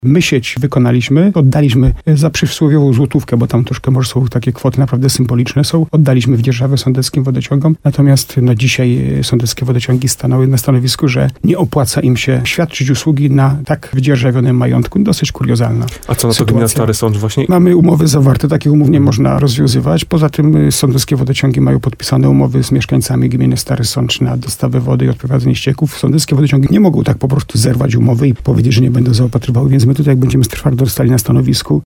Burmistrz Jacek Lelek mówił w programie Słowo za słowo na antenie RDN Nowy Sącz, że spółka nie jest zainteresowana rozbudową sieci na terenie jego gminy, a ponadto podważa sens dostarczania tutejszym mieszkańcom wody i odprowadzania ścieków.